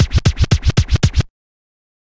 116 BPM Beat Loops Download